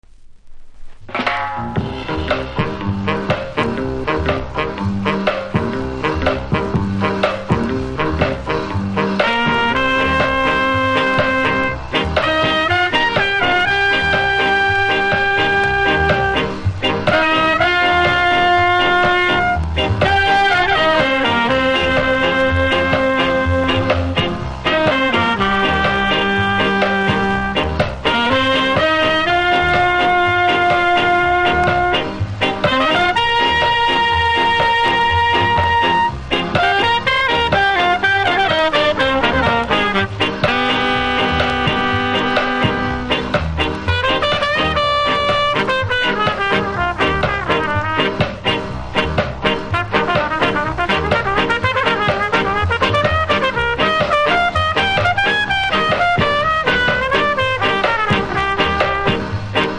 表面少しうすキズぐらいでノイズも少なく良好盤です。